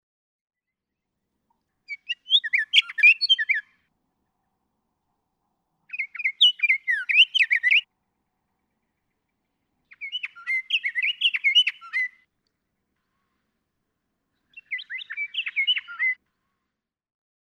Scott's oriole
One example of each of the four different songs from the above recording.
Joshua Tree National Monument.
664_Scott's_Oriole.mp3